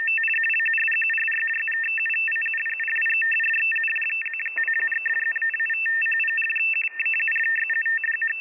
描述：来自韩国之声无线电传输的德语男性新闻阅读器。
标签： 声音 语言 短波 在线无线电接收器 无线电 语音的 韩国的 短波 特温特 大学 上午 德国 男性 新闻阅读器